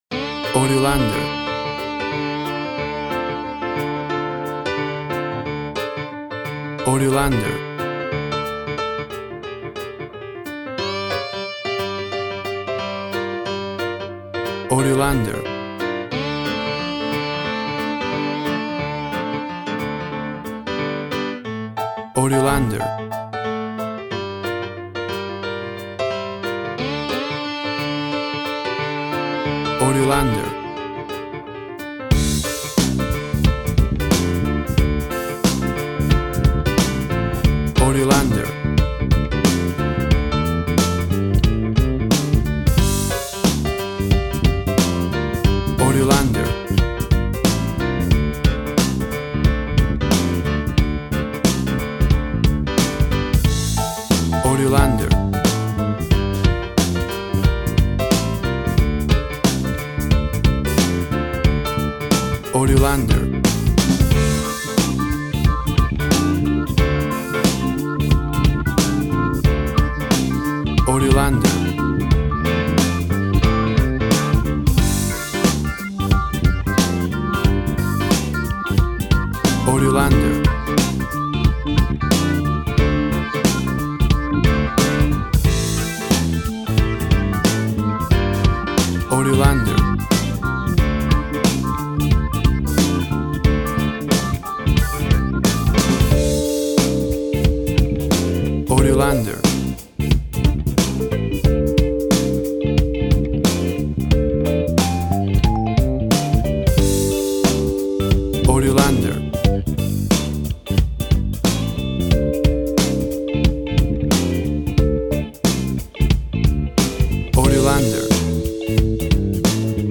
Tempo (BPM) 90